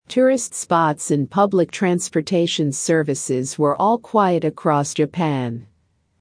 【ややスロー・スピード】
❖ spots and:spots in と発音が似ているので、意味で判断します。